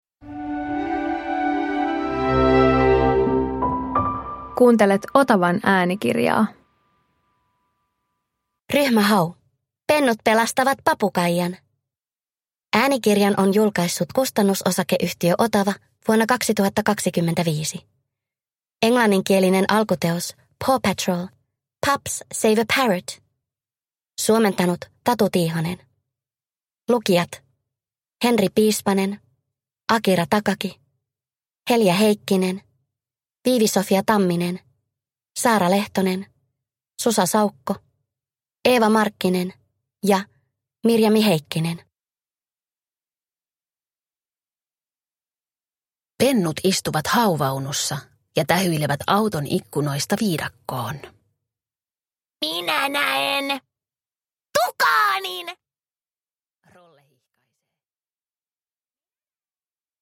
Ryhmä Hau - Pennut pelastavat papukaijan – Ljudbok